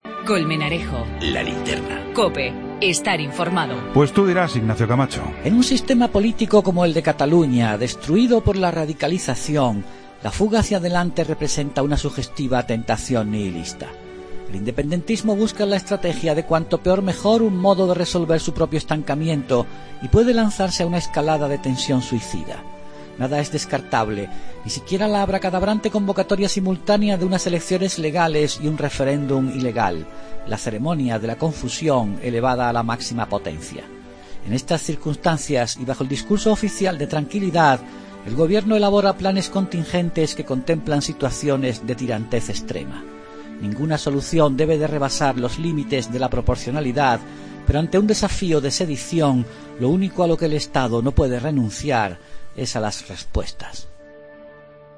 AUDIO: El comentario de Ignacio Camacho sobre la consulta catalana